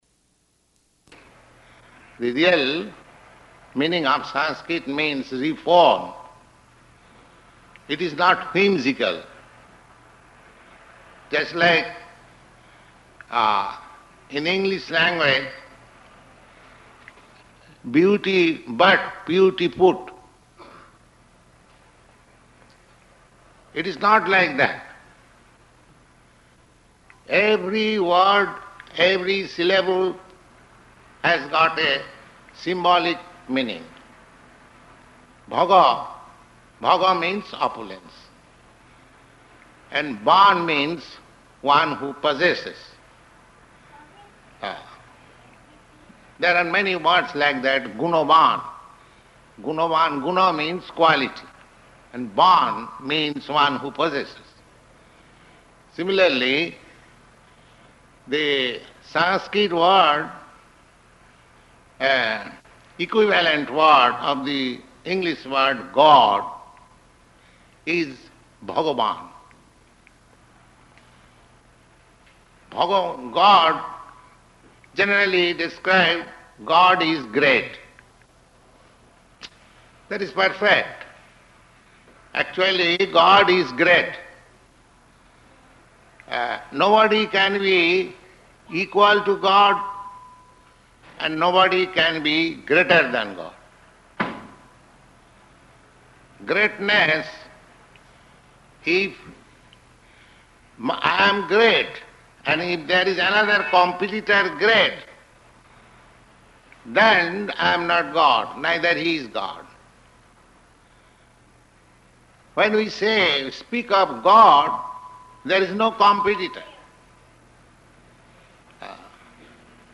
Sunday Feast Lecture
Sunday Feast Lecture --:-- --:-- Type: Lectures and Addresses Dated: January 19th 1969 Location: Los Angeles Audio file: 690119LE-LOS_ANGELES.mp3 Prabhupāda: ...the real meaning of Sanskrit means "reform."